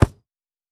Ball Pass Chip.wav